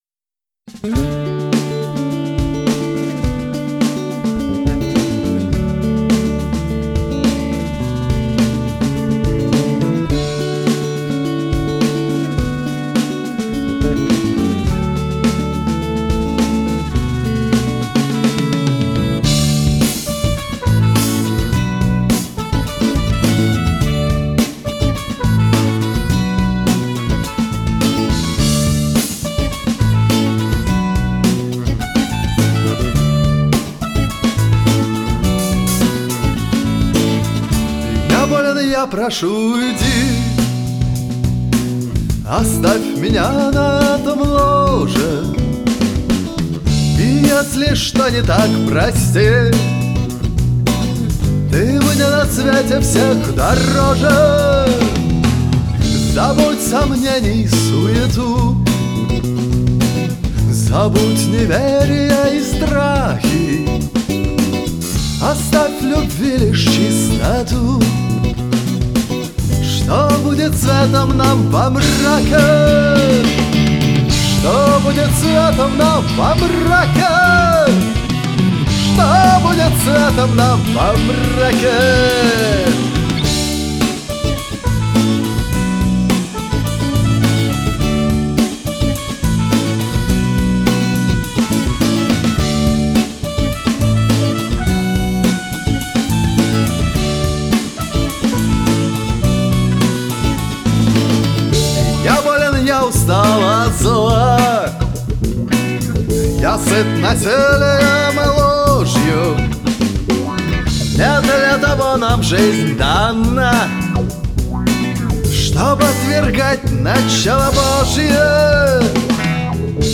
Несколько песен из студии